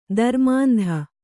♪ dharmāndha